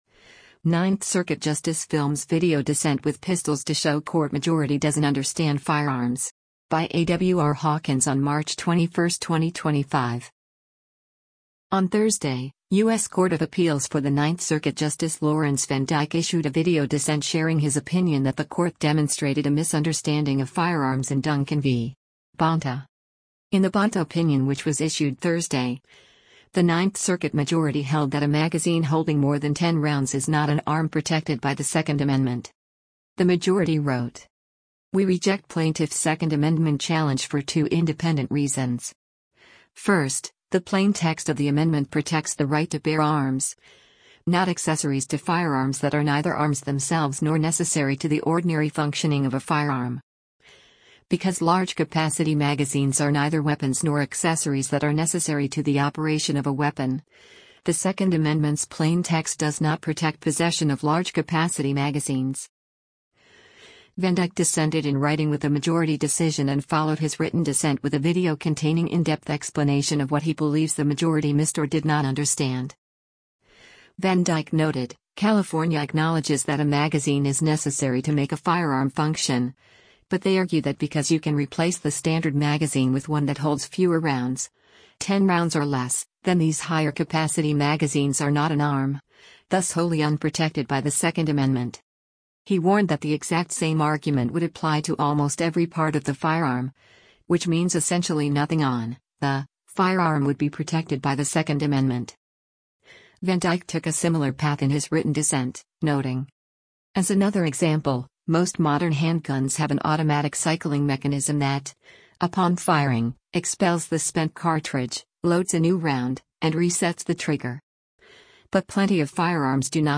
Ninth Circuit Justice Films Video Dissent with Pistols to Show Court Majority Doesn’t Understand Firearms
VanDyke dissented in writing with the majority decision and followed his written dissent with a video containing in-depth explanation of what he believes the majority missed or did not understand.